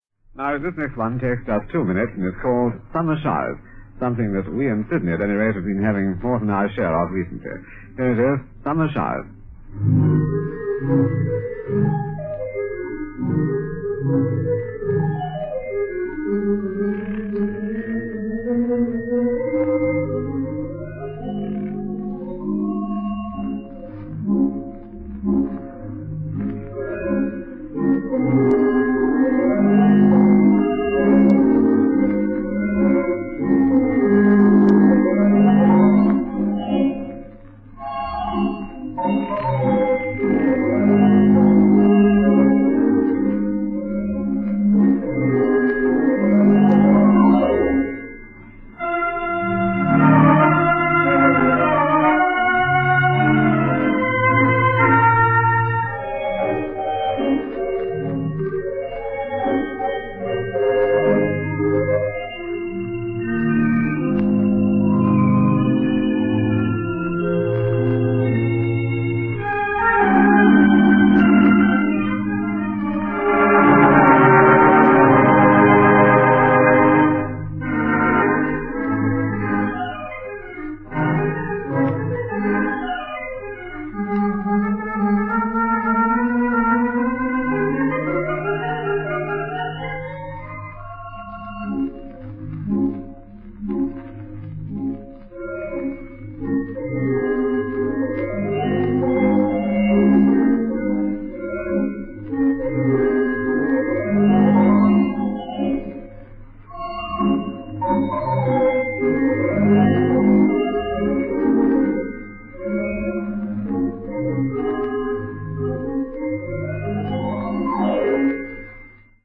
Virtual Radiogram - Australian theatre organ recordings
Most of the files are derived from acetate discs recorded off-air of broadcasts between 1948 and the early 1950s.
Announcements and organists' sign on/off signature tunes have been retained to enhance the listening experience. The recording quality is variable, so these recordings should be appreciated for their archive interest, rather than their audio fidelity. Some of the playing styles may seem a little dated after fifty to seventy-five years, but this is reality - how things were in the Golden Age of the Theatre Organ in Australia.